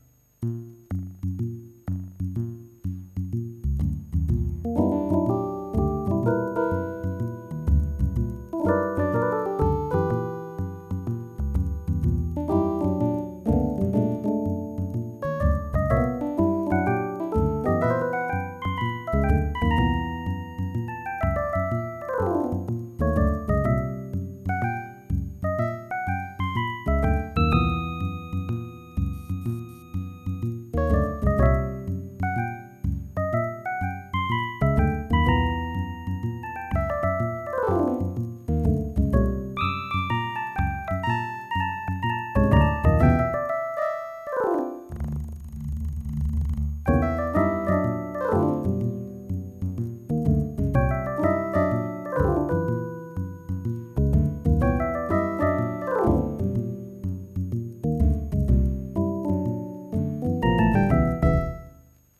Imitation Rhodes electric piano